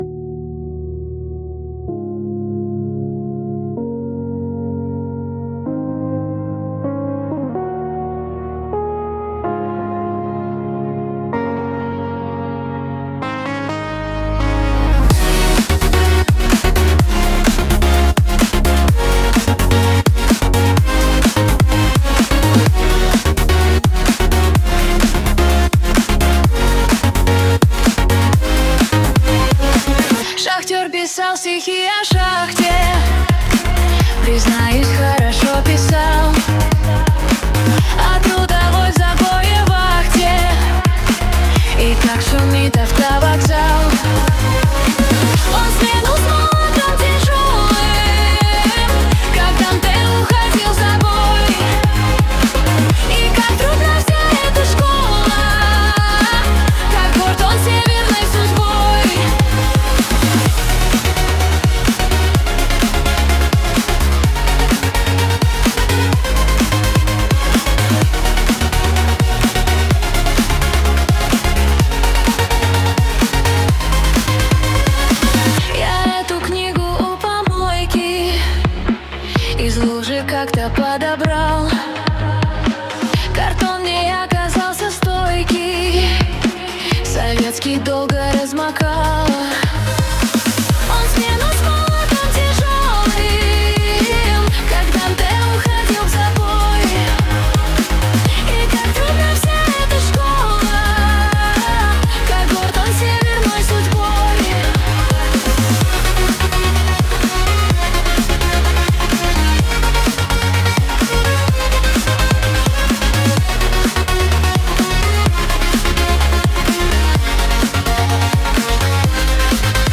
Глитчвейв эмбиент